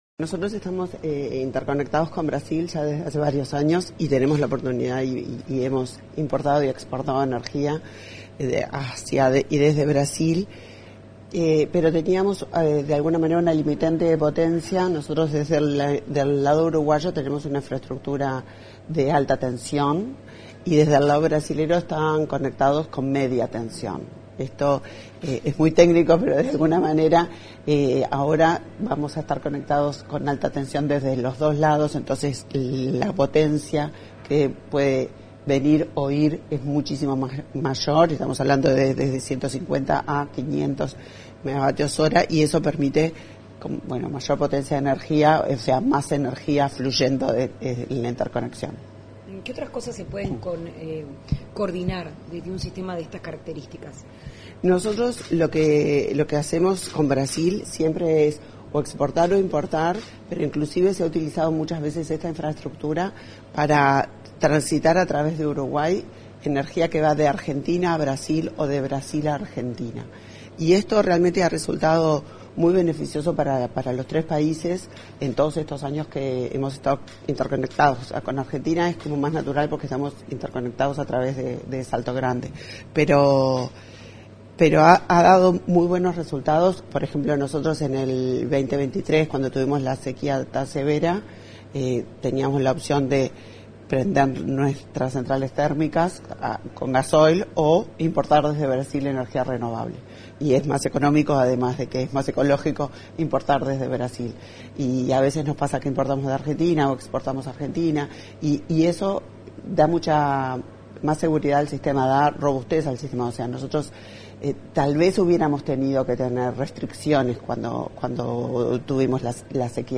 Declaraciones de la ministra de Industria, Energía y Minería, Elisa Facio